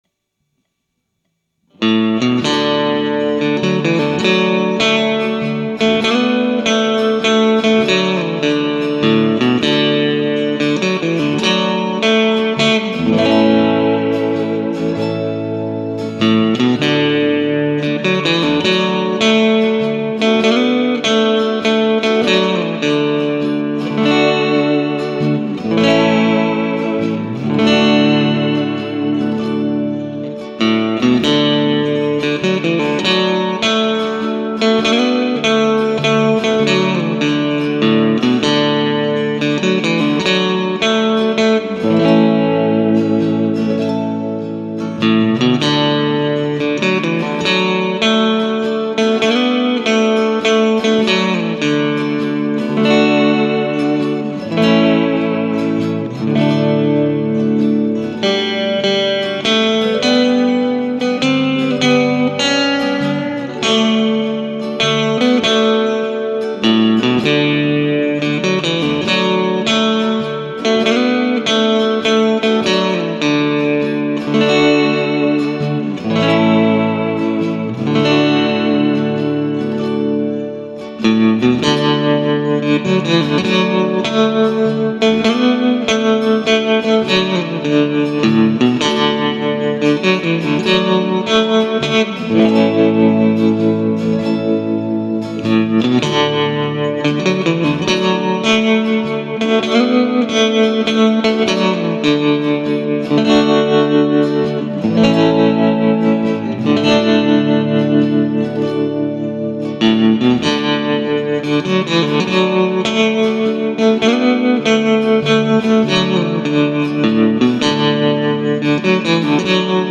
I was messing around with a song I had in my head.
A very mellow tune, almost gospel type of feel to it.